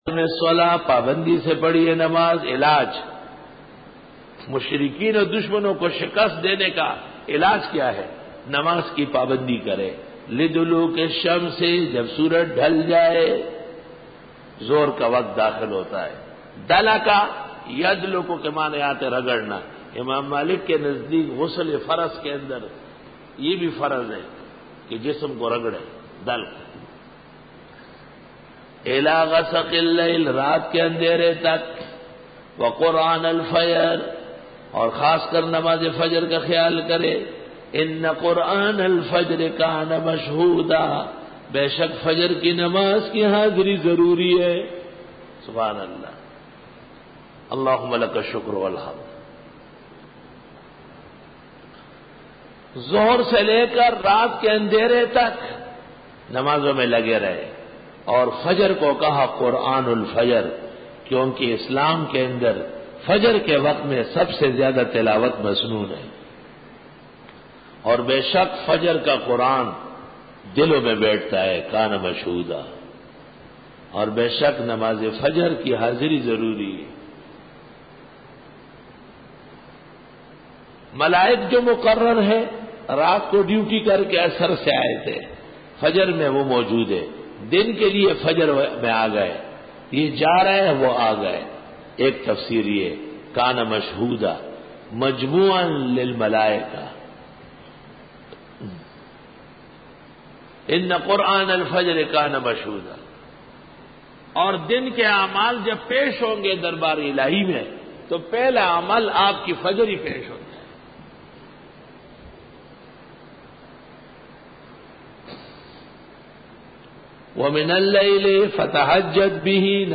Dora-e-Tafseer 2006